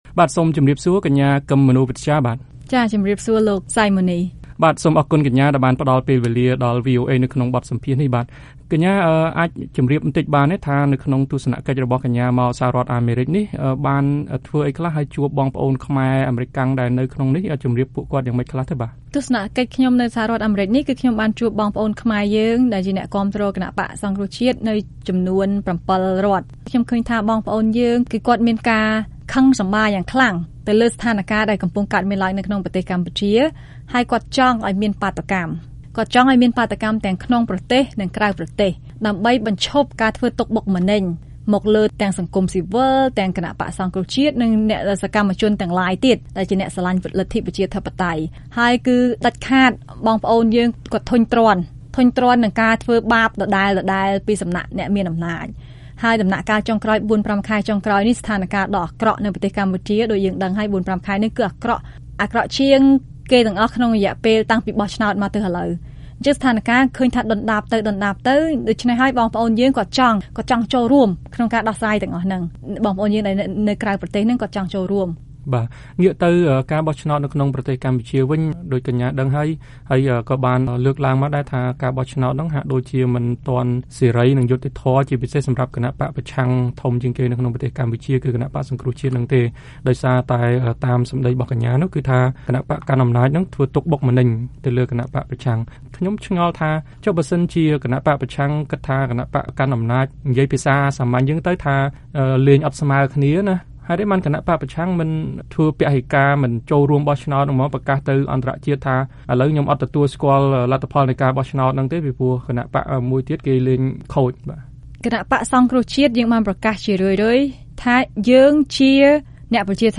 បទសម្ភាសន៍VOA៖ មន្ត្រីបក្សប្រឆាំងថា ពលរដ្ឋខ្មែរទាំងក្នុងនិងក្រៅស្រុក ចង់ឲ្យមានបាតុកម្ម ដើម្បីទាមទារការបោះឆ្នោតដោយសេរីនិងយុត្តិធម៌ (ភាគ១)